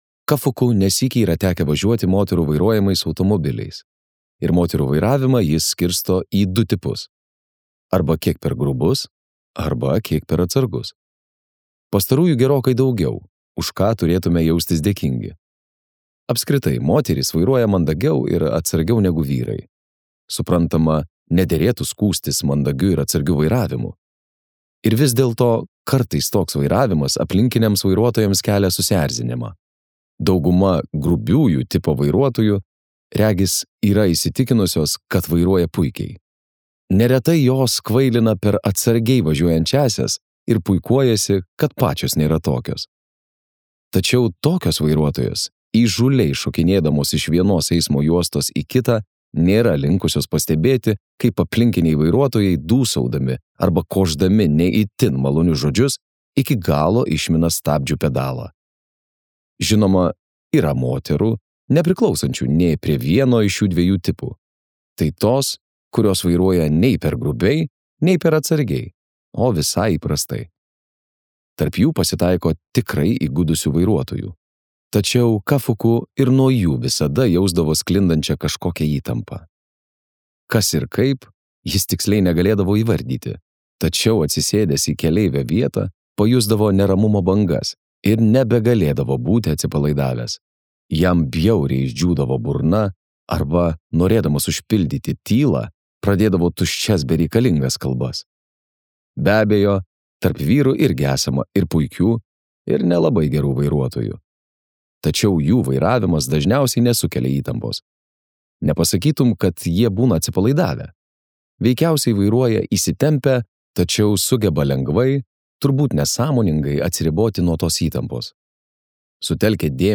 Vaidoto Baumilos įgarsinta audioknyga „Vyrai be moterų“ – jautrus ir melancholiškas japonų rašytojo Haruki Murakami apsakymų rinkinys apie vienišumą, meilės ilgesį ir vyrų gyvenimus be moterų.